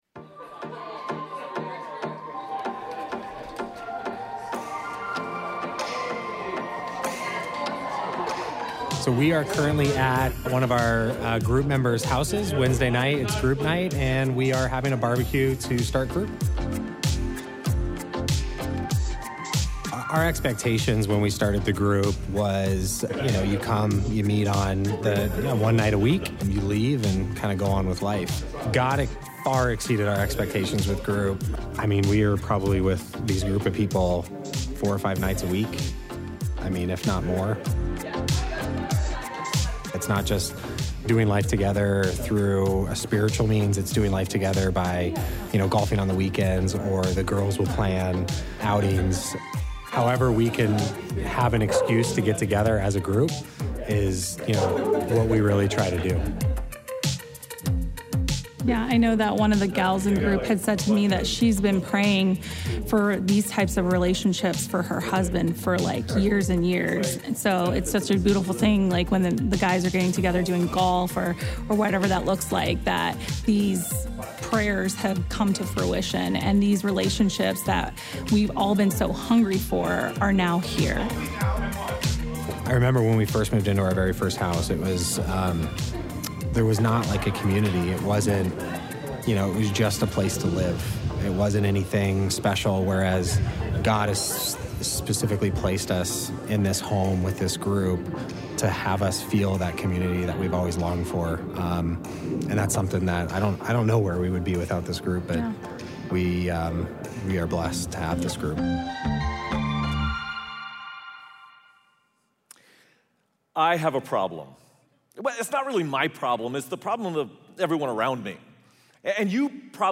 Missed church service this weekend or want to hear the message again?